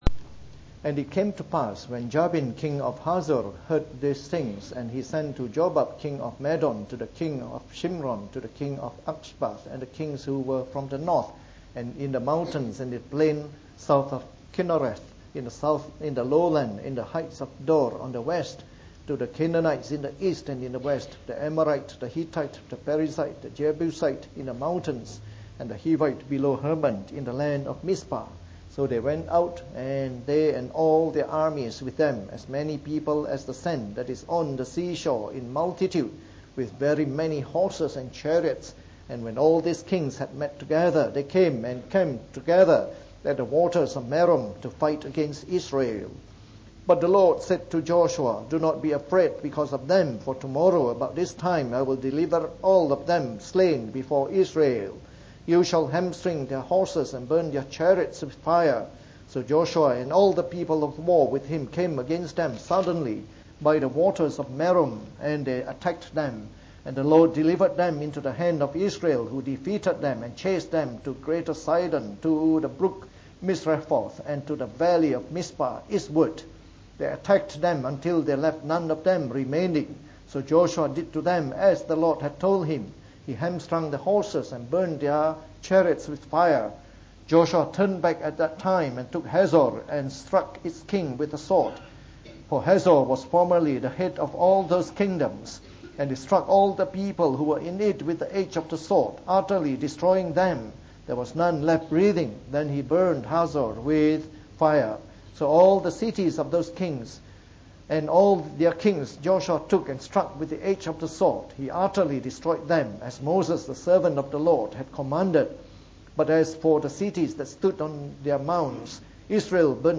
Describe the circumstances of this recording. From our series on the Book of Joshua delivered in the Morning Service.